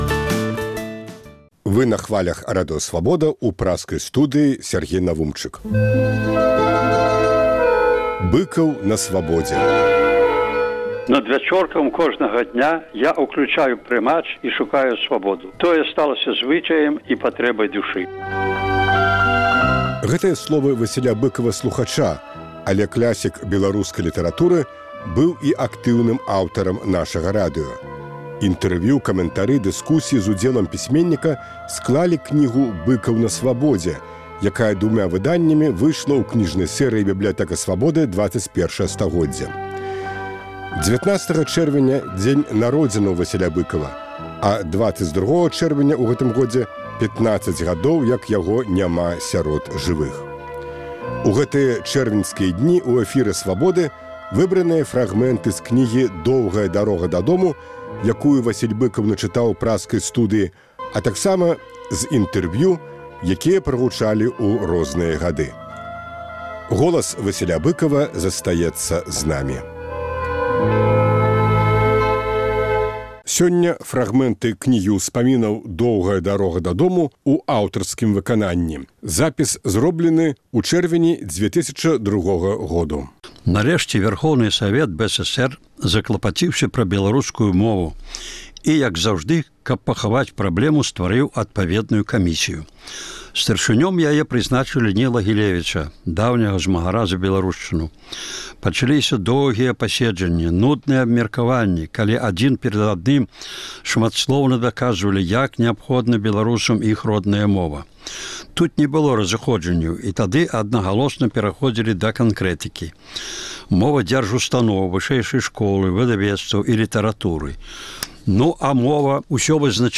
У гэтыя дні ў этэры «Свабоды» – выбраныя фрагмэнты з кнігі «Доўгая дарога дадому», якую Васіль Быкаў начытаў у праскай студыі, а таксама з інтэрвію, якія прагучалі ў розныя гады.